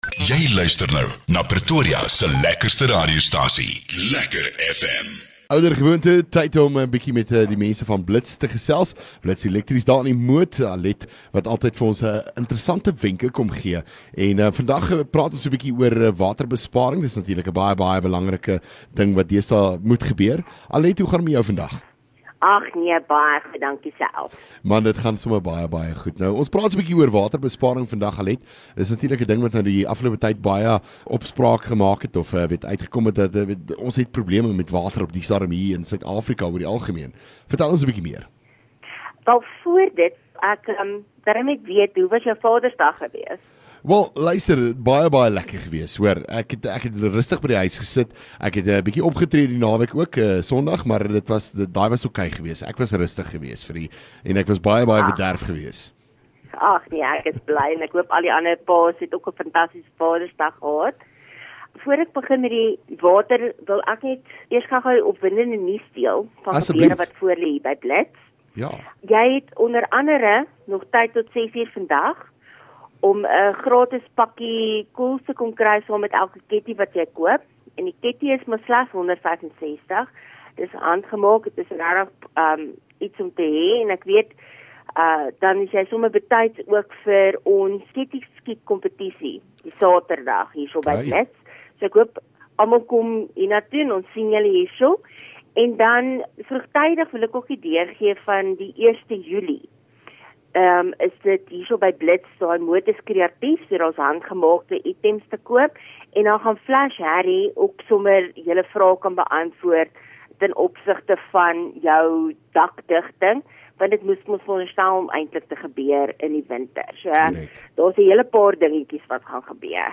LEKKER FM | Onderhoude 19 Jun Blits Elektrisiëns